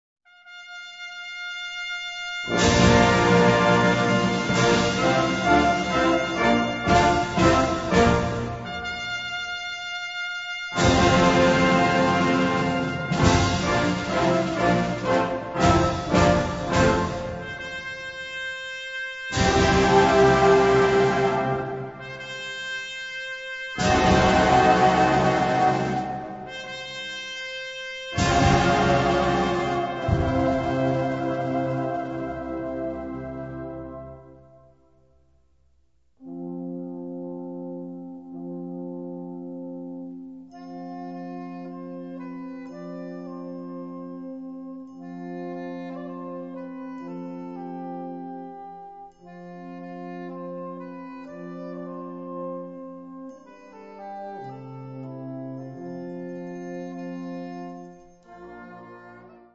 Subcategorie Rhapsody
Bezetting Ha (harmonieorkest)